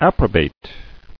[ap·pro·bate]